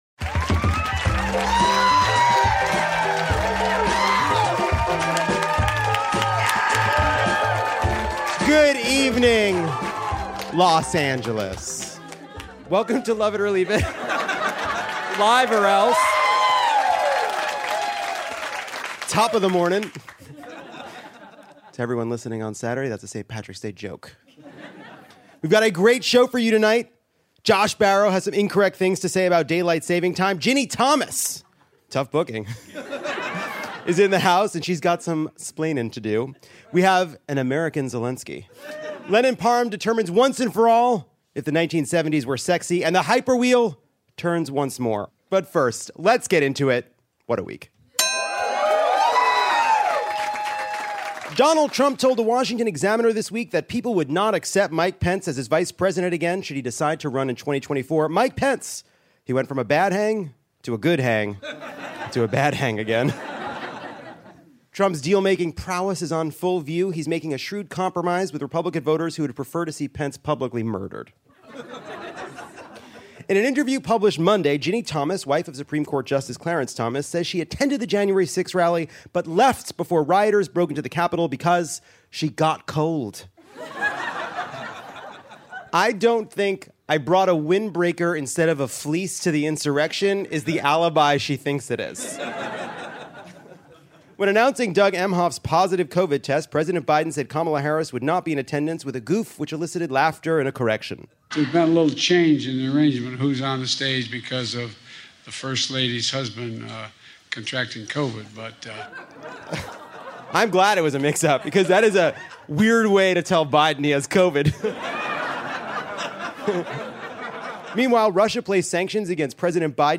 Live from Dynasty Typewriter, Ginni Thomas (Pandora Boxx) storms the barricade to bring us good, solid marriage advice. Josh Barro and Lovett go toe-to-sleepy toe on Daylight Saving Time.